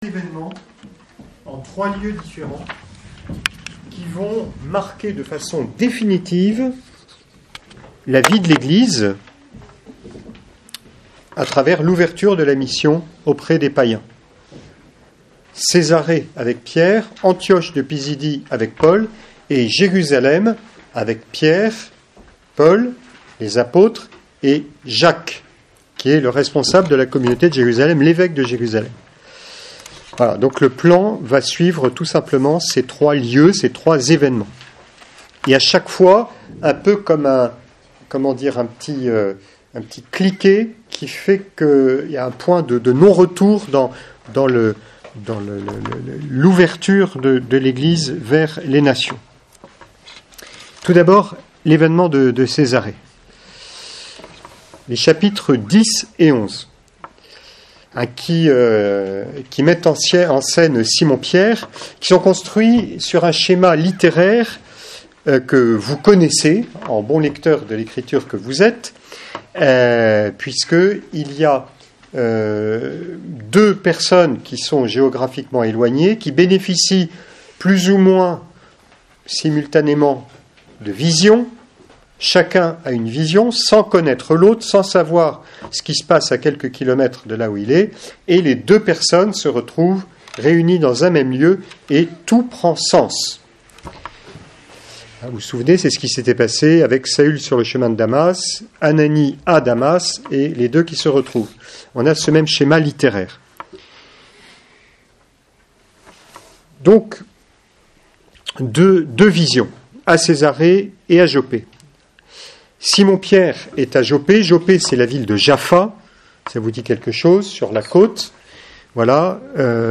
3ème cours